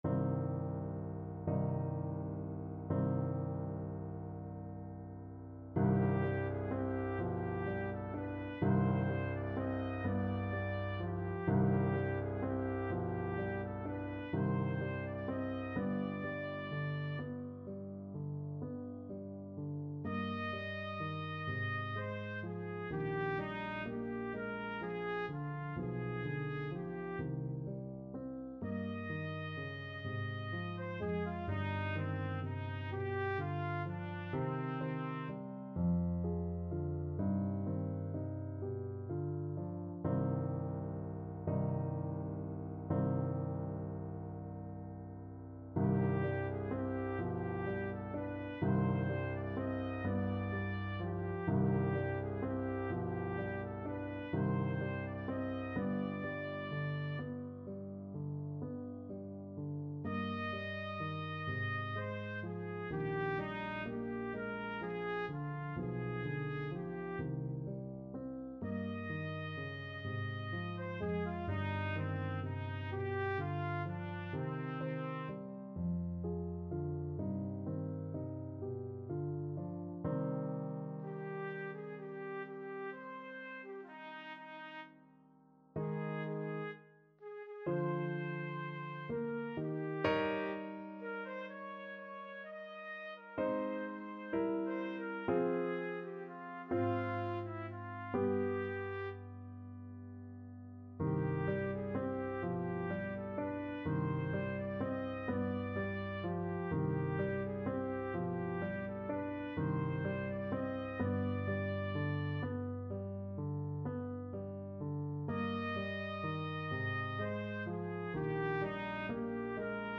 Classical Schubert, Franz Der Abend, D.108 Trumpet version
C minor (Sounding Pitch) D minor (Trumpet in Bb) (View more C minor Music for Trumpet )
= 42 Andante con moto (View more music marked Andante con moto)
6/8 (View more 6/8 Music)
Trumpet  (View more Intermediate Trumpet Music)
Classical (View more Classical Trumpet Music)